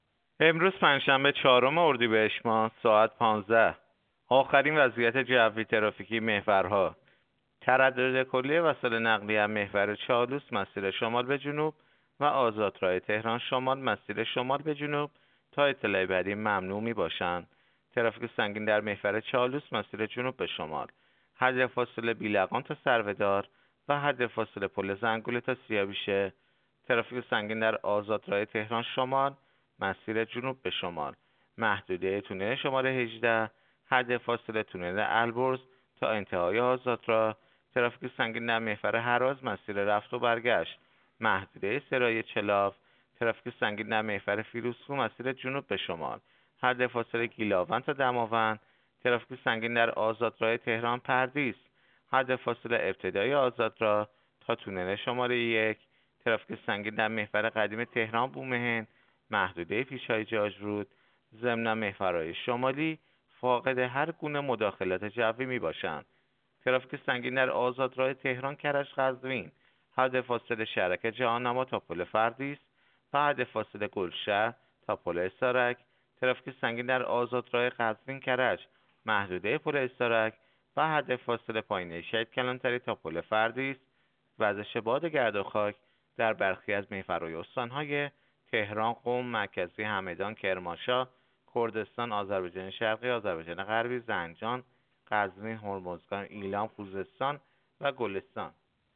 گزارش رادیو اینترنتی از آخرین وضعیت ترافیکی جاده‌ها ساعت ۱۵ چهارم اردیبهشت؛